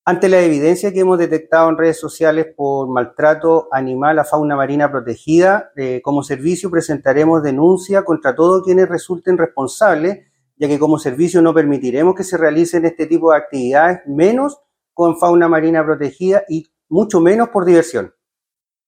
Por su parte, el director regional del Servicio Nacional de Pesca y Acuicultura (Sernapesca), Rafael Hernández, aseguró que no permitirán perjuicios a fauna marina protegida, mucho menos por diversión.